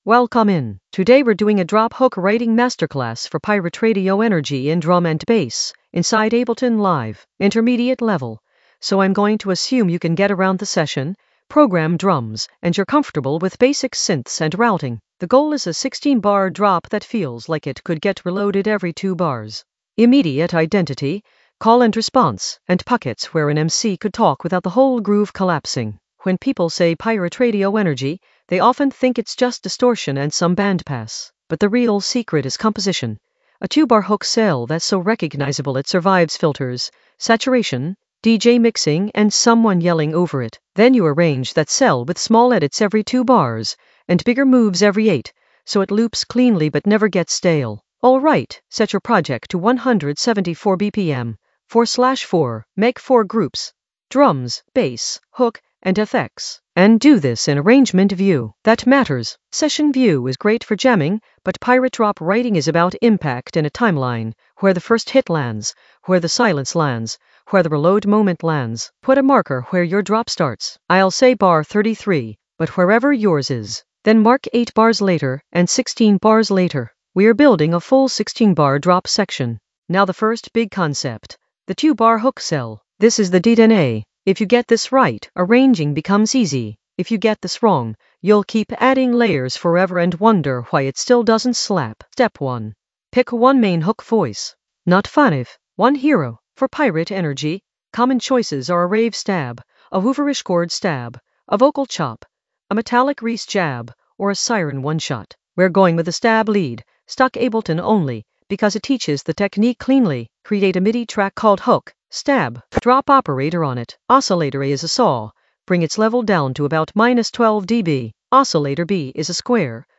Narrated lesson audio
The voice track includes the tutorial plus extra teacher commentary.
drop-hook-writing-masterclass-for-pirate-radio-energy-intermediate-composition.mp3